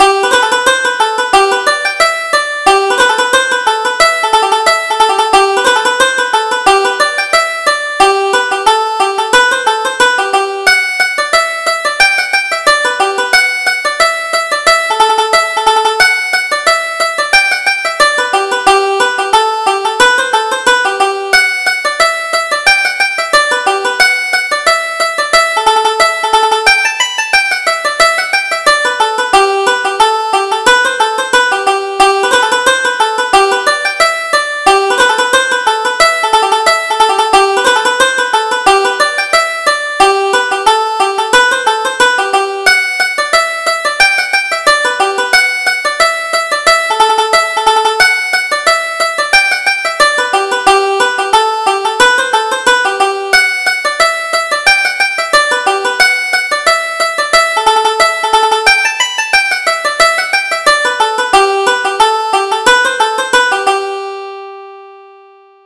Reel: The Woman of the House